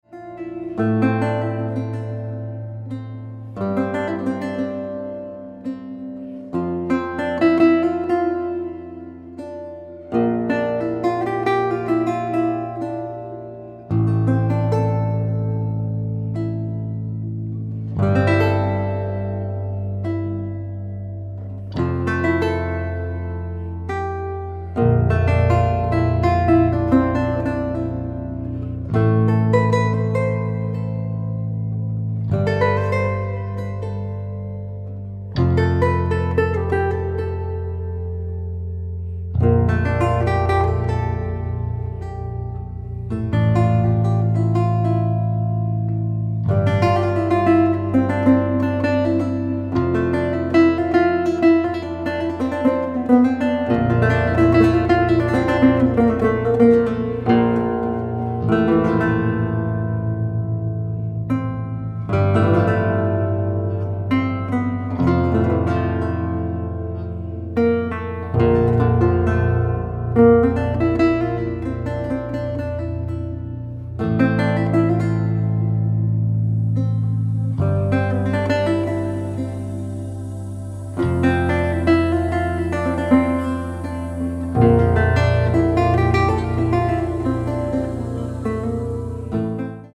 Contemporary
Lute